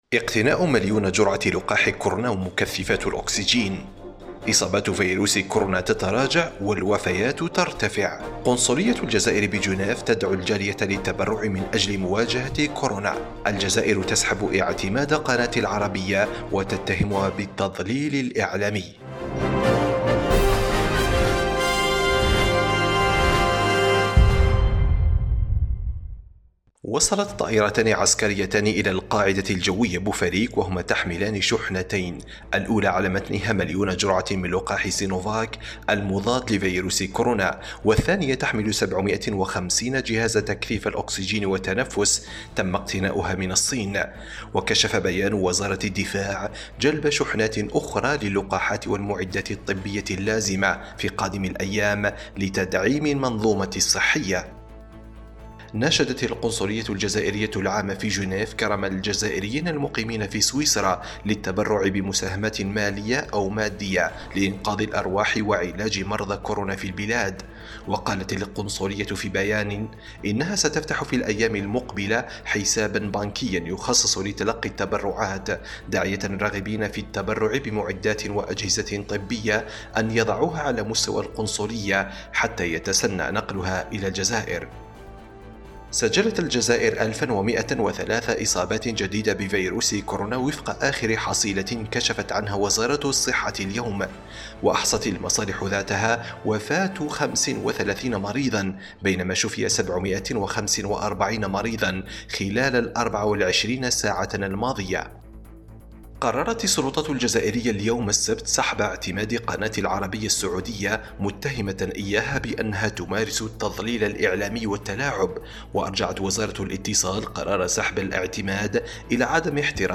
النشرة اليومية: إصابات كورونا تنخفض والوفيات ترتفع – أوراس